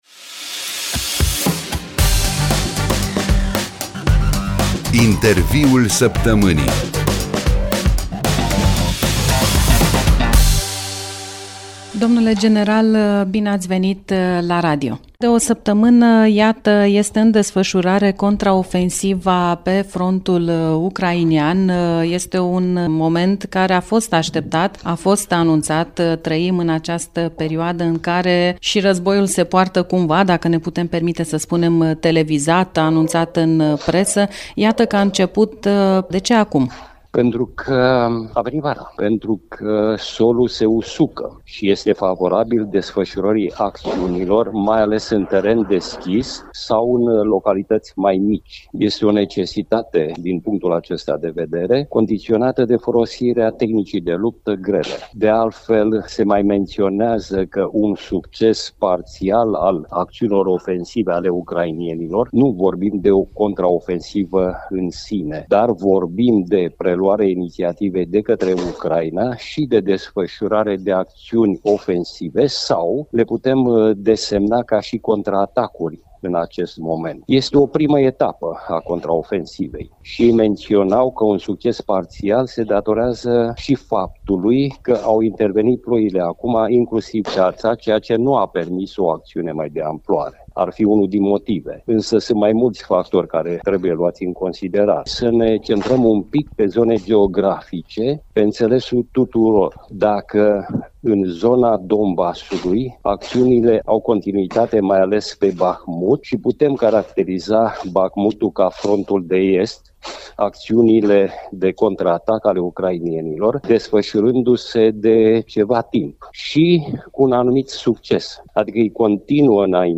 la „Interviul săptămânii”